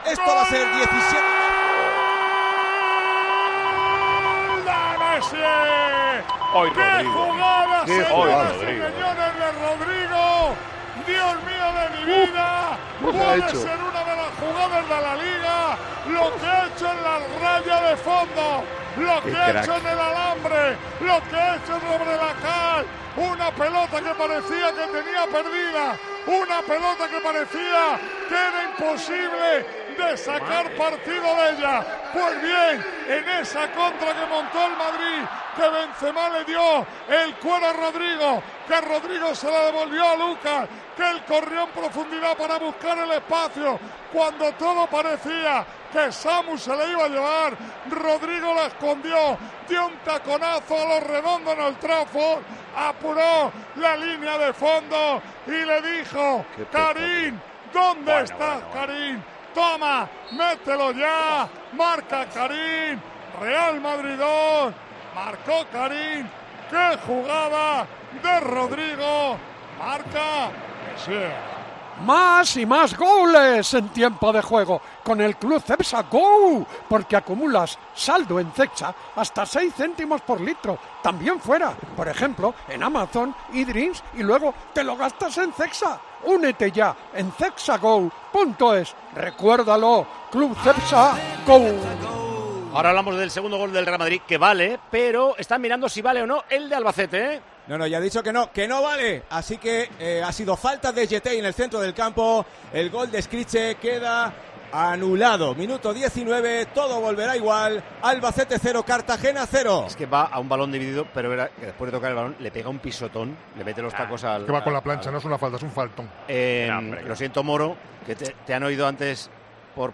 Con Paco González, Manolo Lama y Juanma Castaño